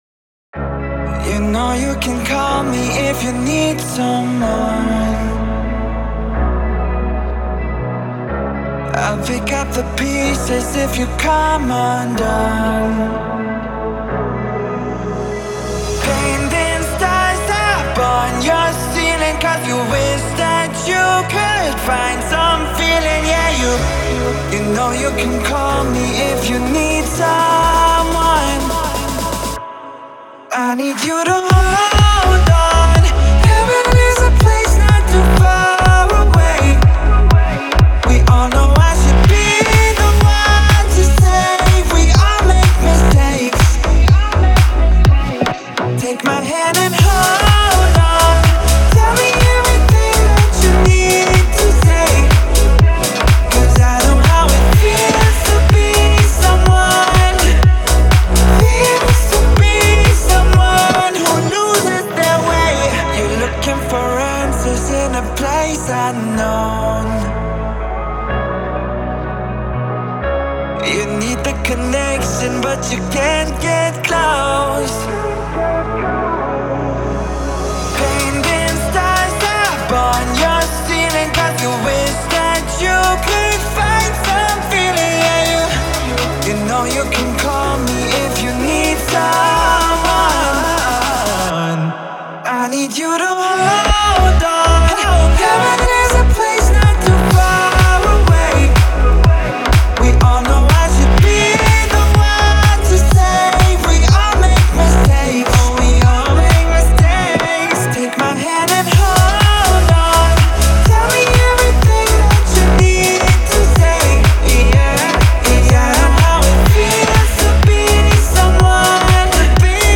это трек в жанре электронного попа